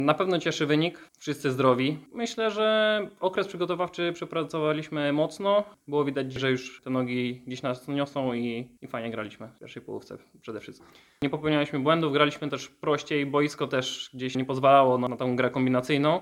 Ostatni sprawdzian podsumowuje trener Marek Saganowski: